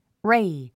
ray 発音 réi レイ